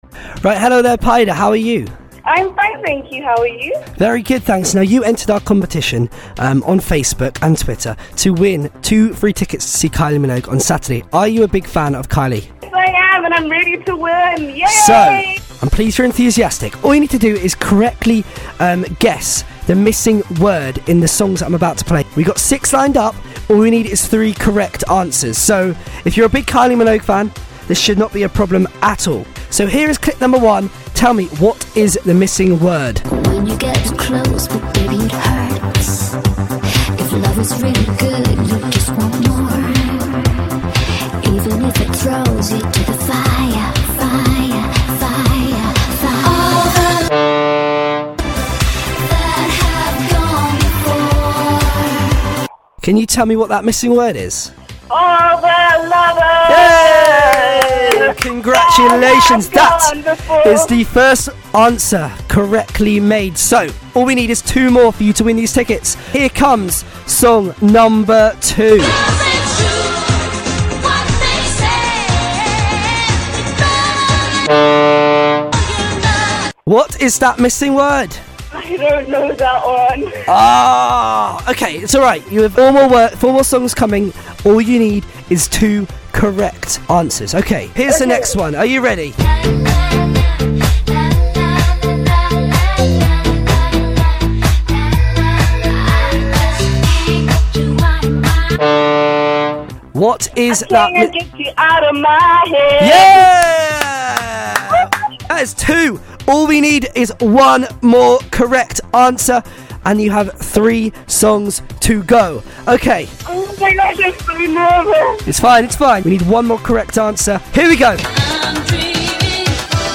live on URN!